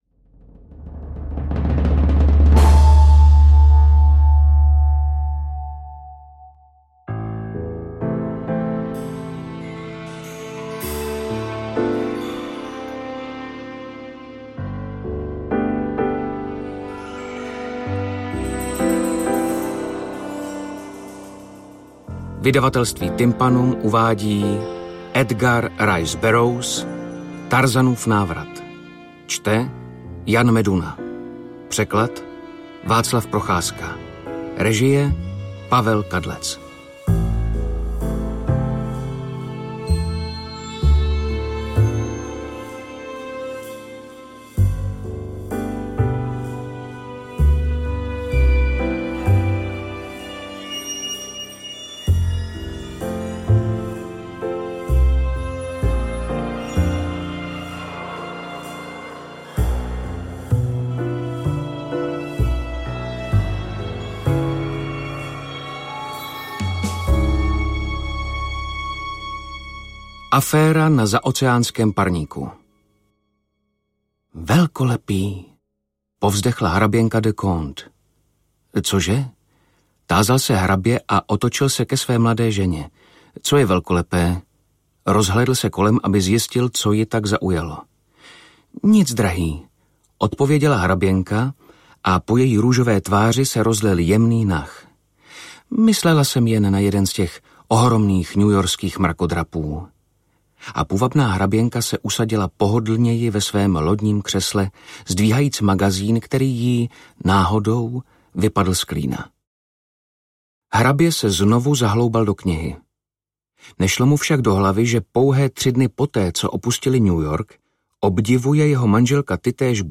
AudioKniha ke stažení, 26 x mp3, délka 8 hod. 21 min., velikost 458,5 MB, -